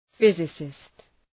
{‘fızısıst}